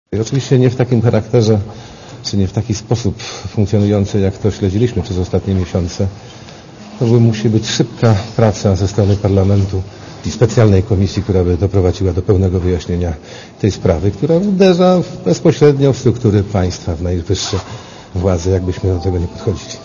Komentarz audio
Również i tę sprawę Kalinowski omawia w Sejmie na spotkaniu partii opozycyjnych.